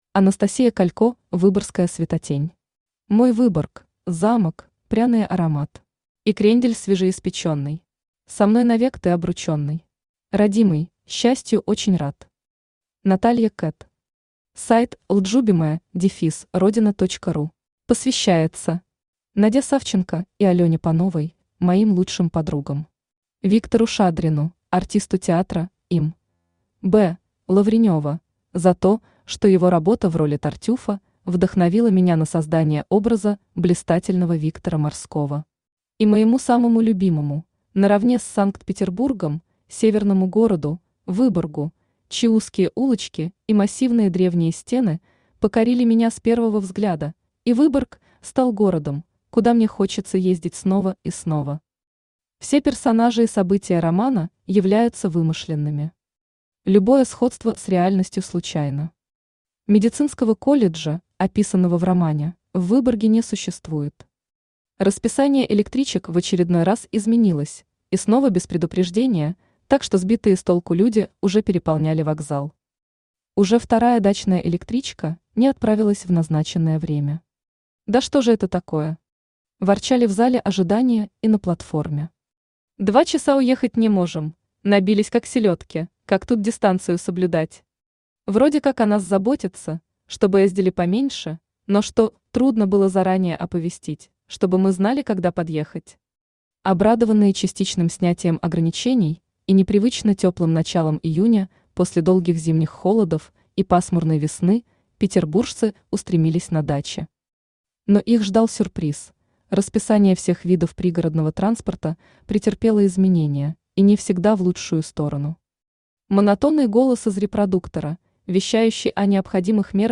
Aудиокнига Выборгская светотень Автор Анастасия Александровна Калько Читает аудиокнигу Авточтец ЛитРес.